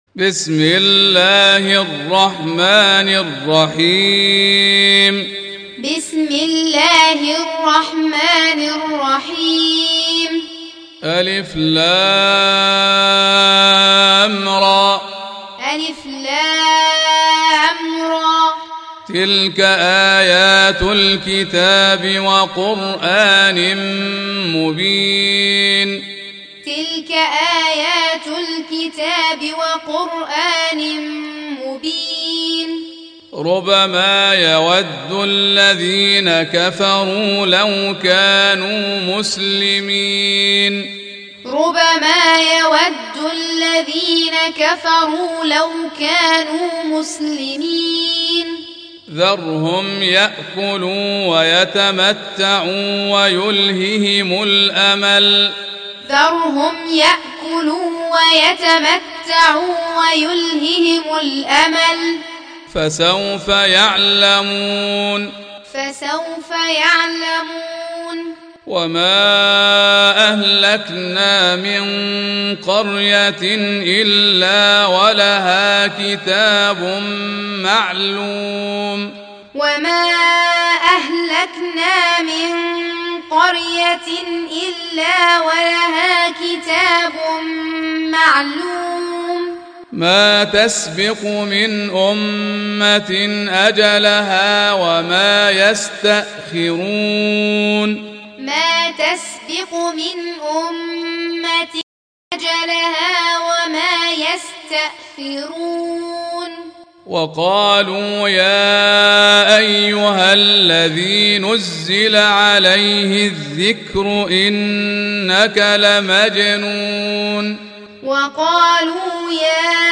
Surah Repeating تكرار السورة Download Surah حمّل السورة Reciting Muallamah Tutorial Audio for 15. Surah Al-Hijr سورة الحجر N.B *Surah Includes Al-Basmalah Reciters Sequents تتابع التلاوات Reciters Repeats تكرار التلاوات